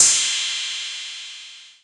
cch_10_perc_crash_high_long_synthetic.wav